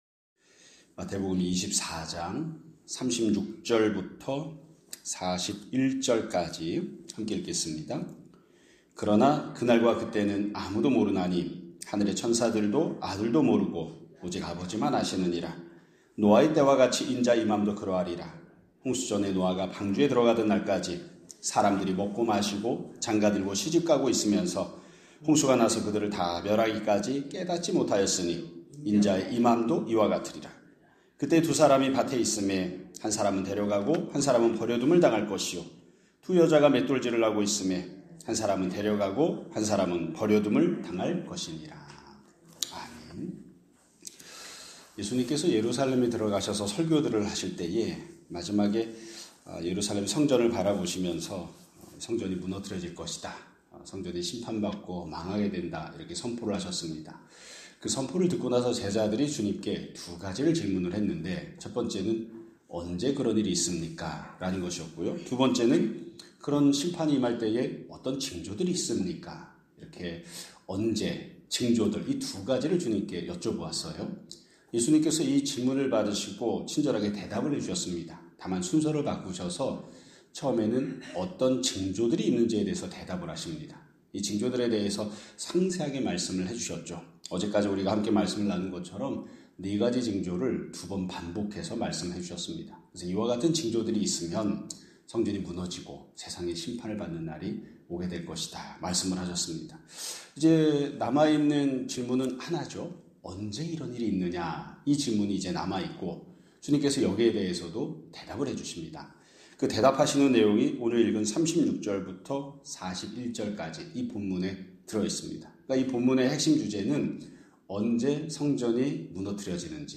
2026년 3월 13일 (금요일) <아침예배> 설교입니다.